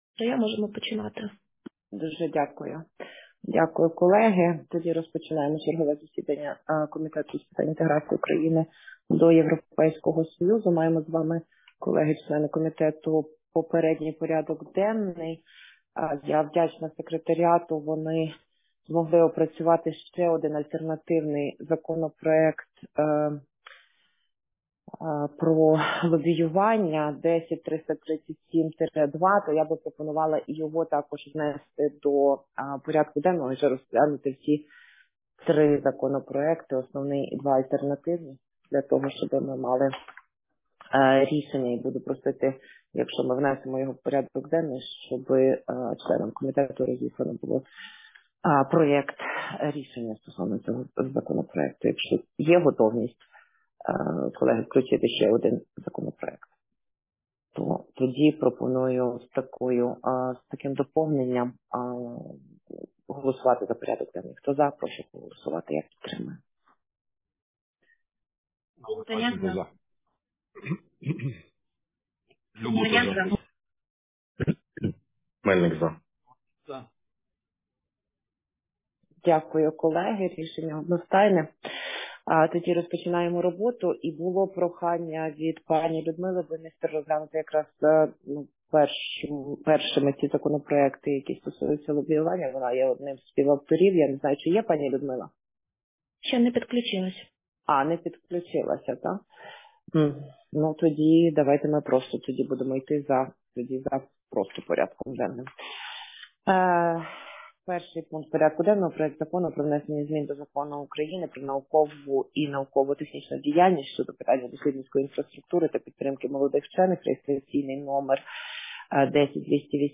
Аудіозапис засідання Комітету 4 січня 2024 року